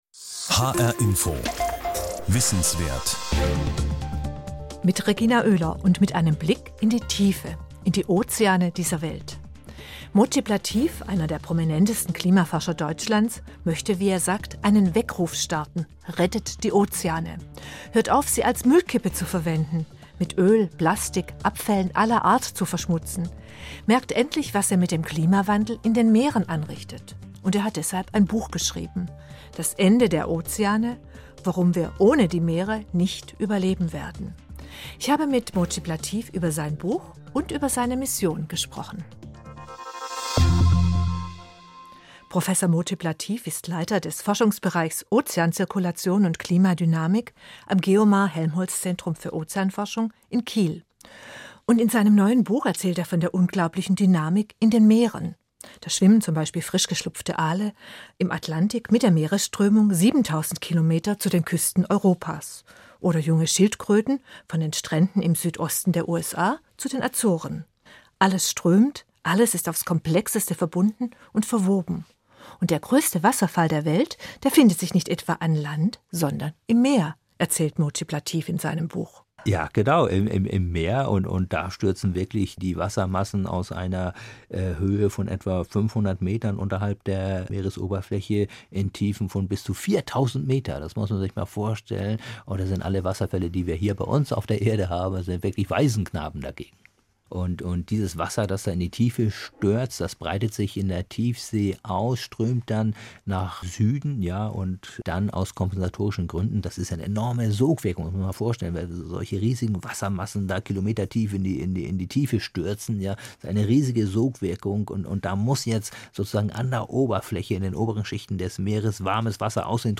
Titel "Rettet die Ozeane" - Ein Gespräch mit dem Klimaforscher Mojib Latif Beschreibung/Kommentar Mojib Latif war einer der prominentesten Klimaforscher Deutschlands - und einer der eindringlichsten Warner vor den Folgen des Klimawandels.